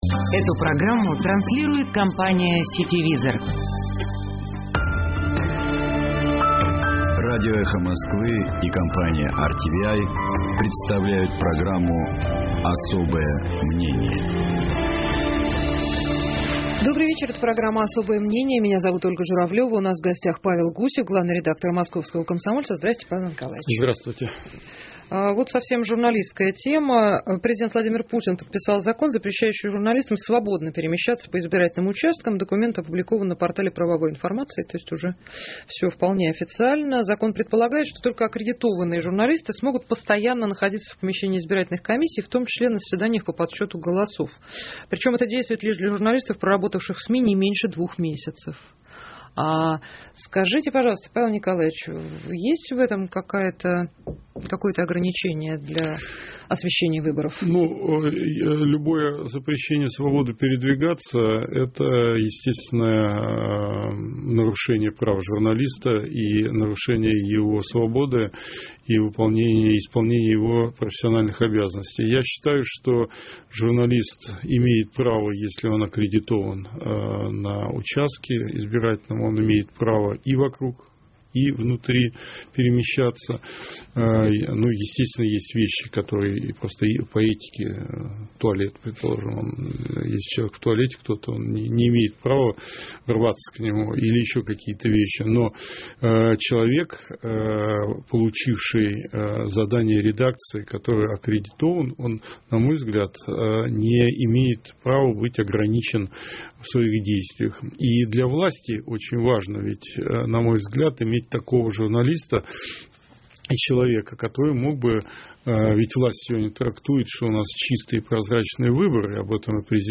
У нас в гостях Павел Гусев, главный редактор газеты «Московский комсомолец».